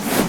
player_broom_attack.ogg